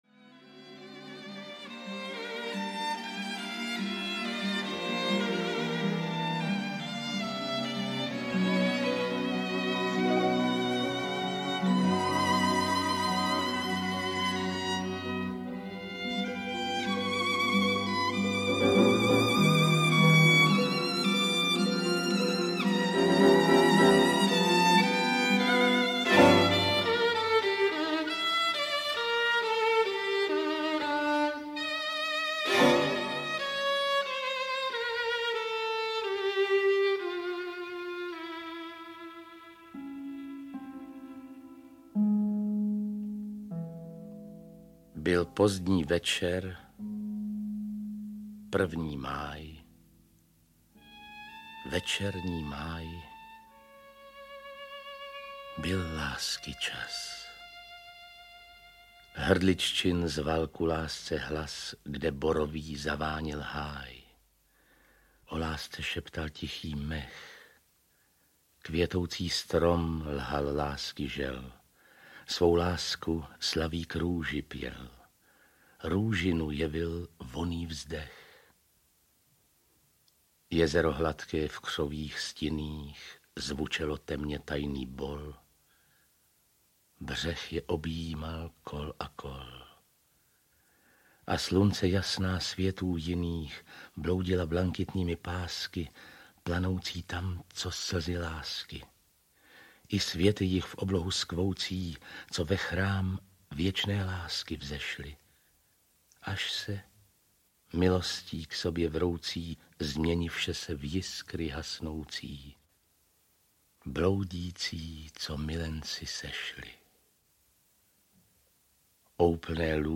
Výběr z díla uhrančivě geniálního básníka audiokniha
Ukázka z knihy